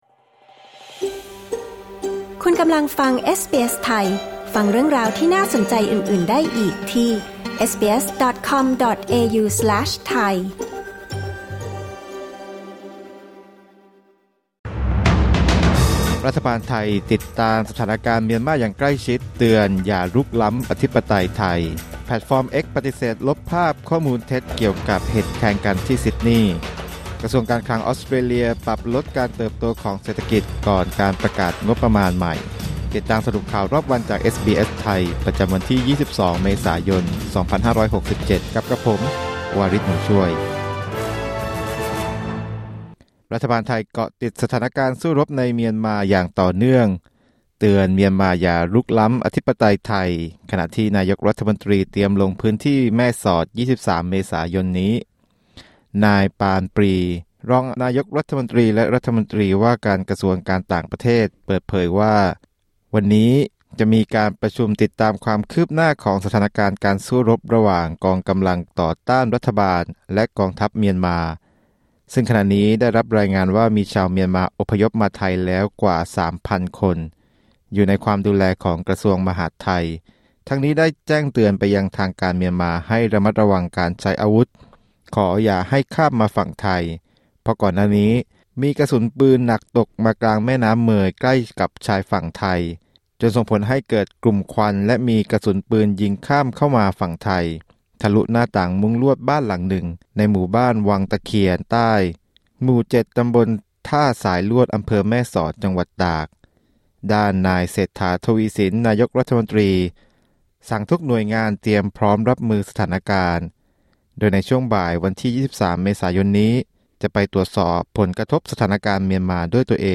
สรุปข่าวรอบวัน 22 เมษายน 2567